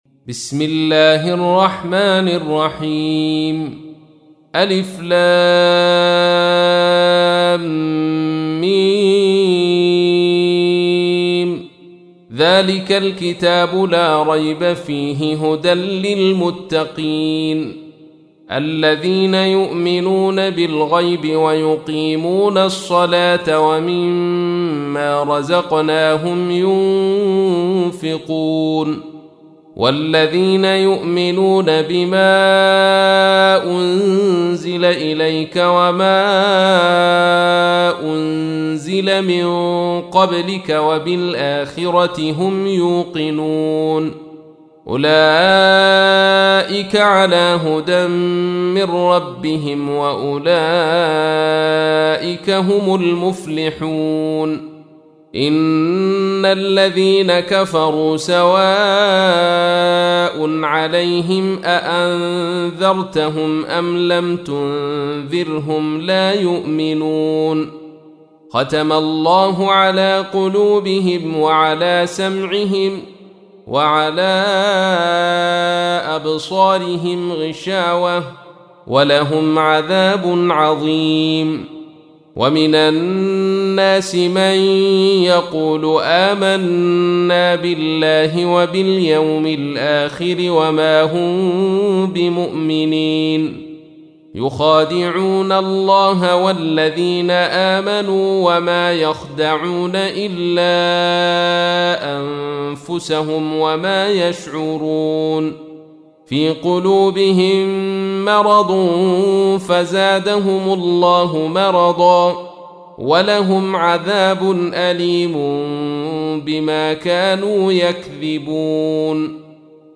تحميل : 2. سورة البقرة / القارئ عبد الرشيد صوفي / القرآن الكريم / موقع يا حسين